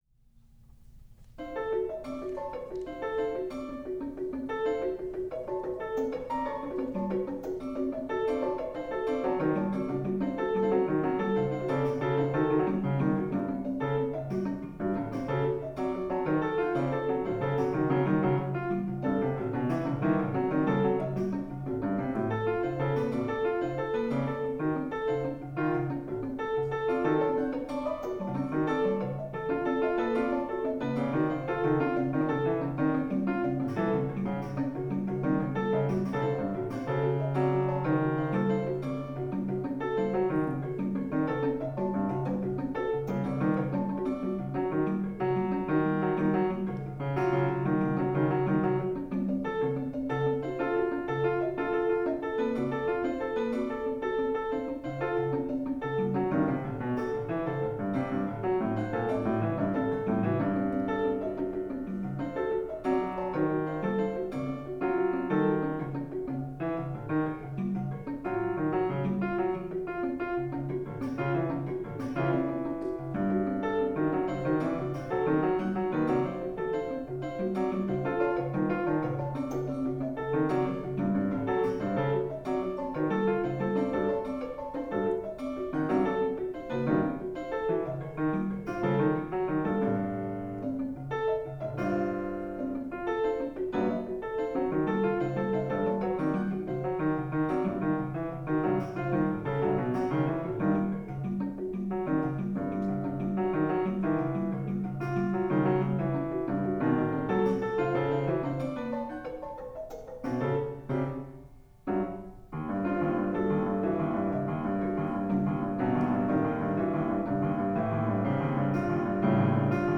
midi keyboard computer, player piano [10']